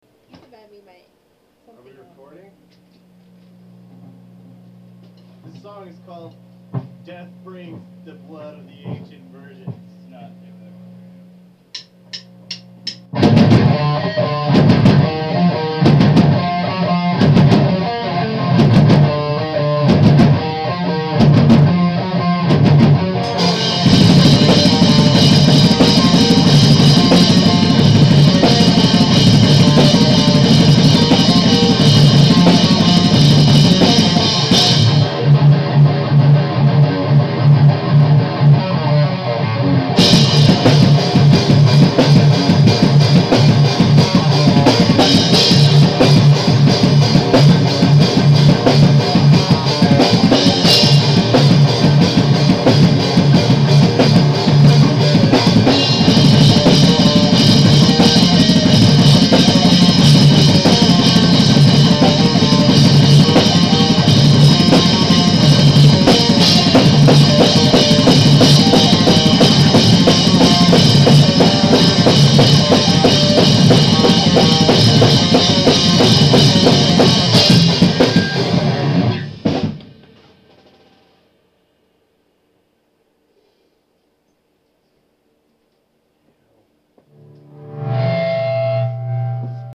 at band practice today